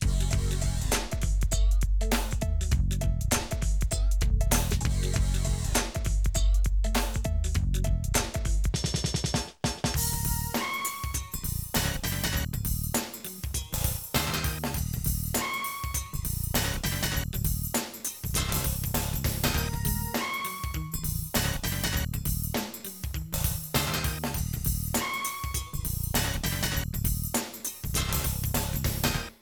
Sound Factory: RAP